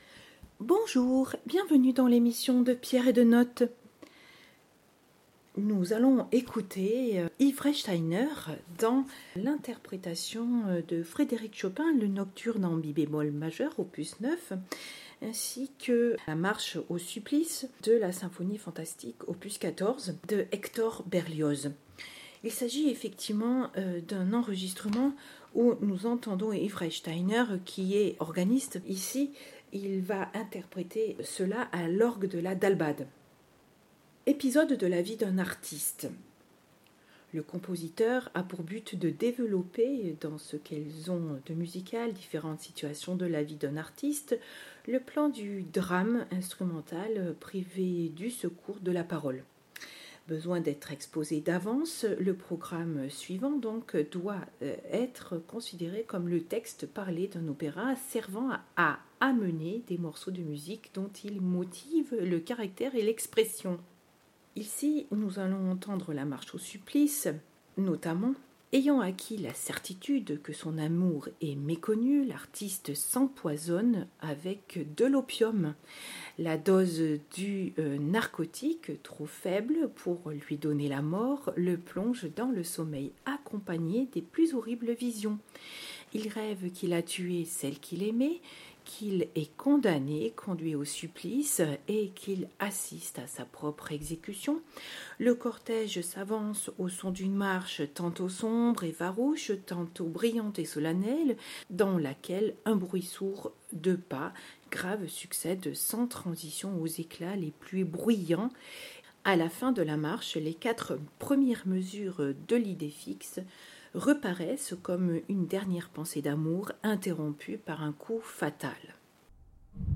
dpdn-_chopin_et_berlioz_a_lorgue_de_la_dalbade.mp3